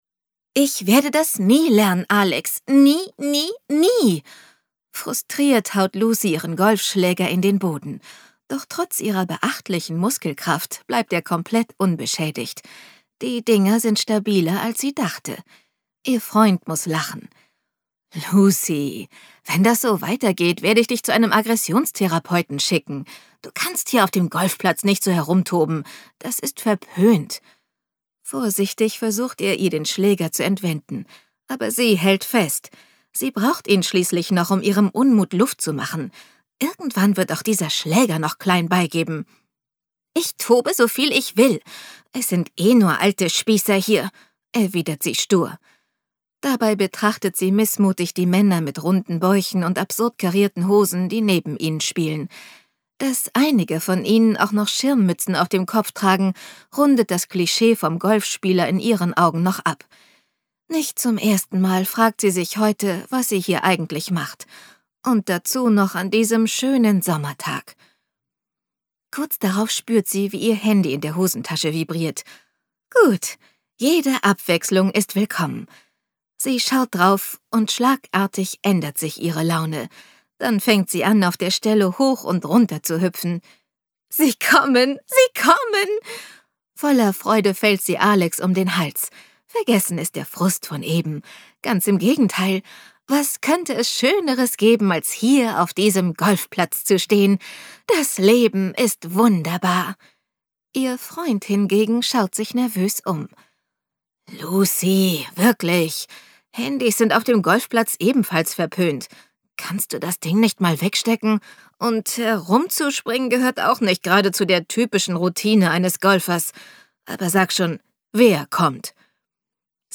Infos zum Hörbuch
Hoerprobe_Skandal_im_Chalet_am_See.mp3